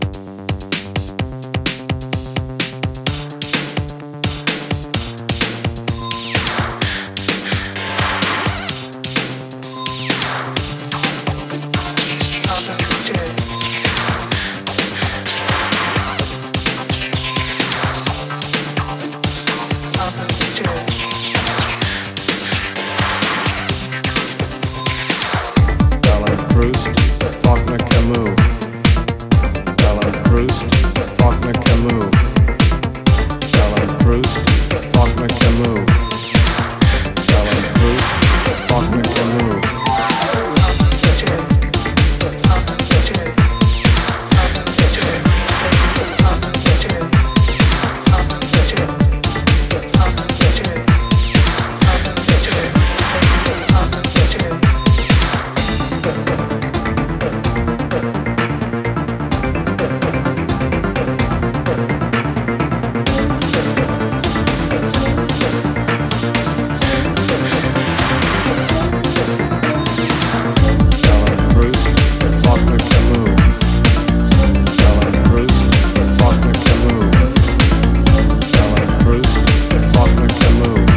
a finely arranged and tuned tech house anthem